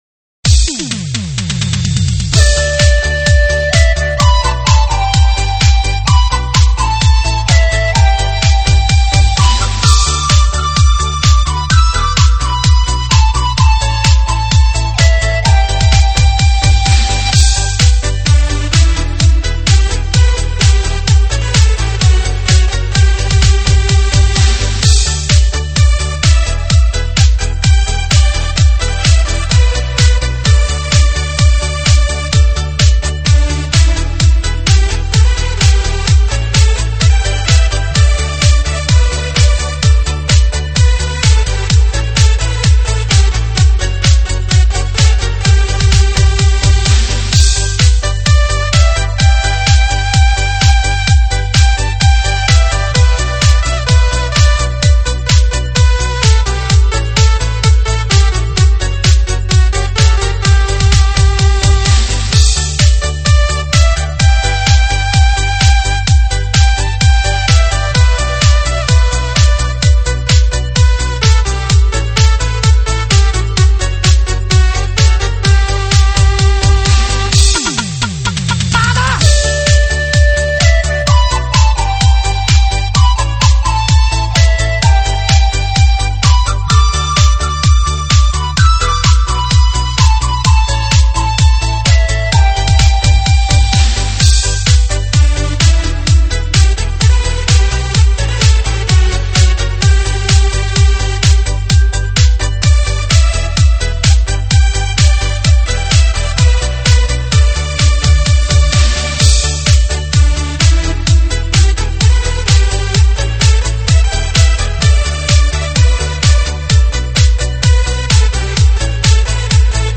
舞曲类别：电子琴